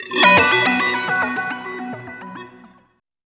69 GTR 4  -L.wav